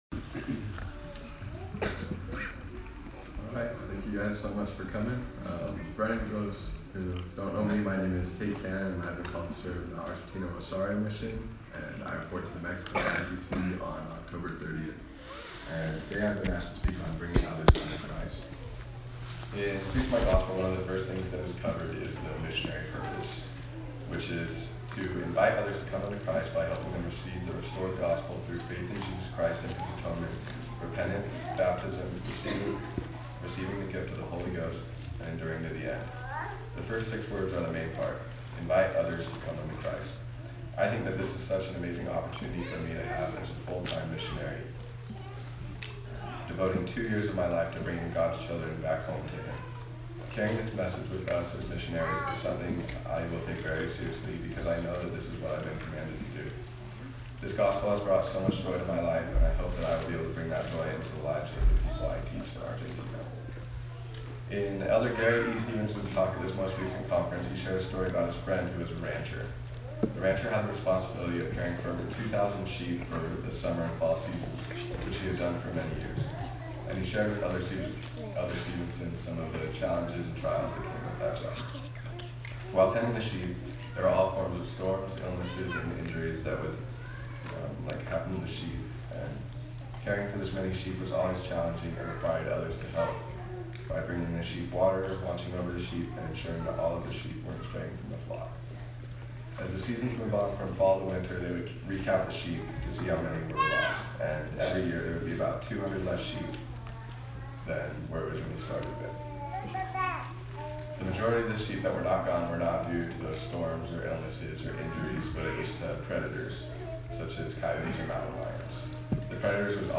Farewell Address